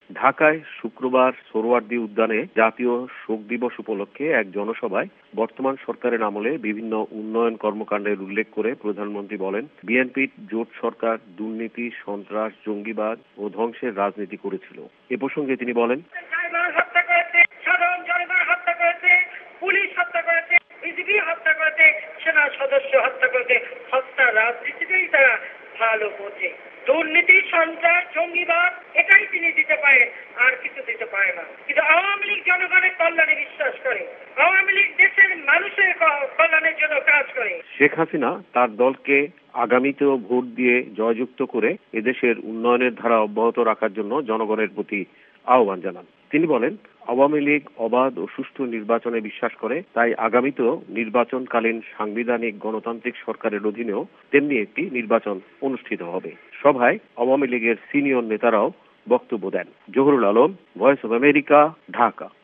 বাংলাদেশের প্রধানমন্ত্রী শেখ হাসিনা আজ ঢাকায় এক জনসমাবেশে ভাষণ দেন
BD Prime Minister Hasina addressed public meeting